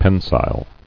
[pen·sile]